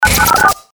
FX-868-PHONE-BREAKER
FX-868-PHONE-BREAKER.mp3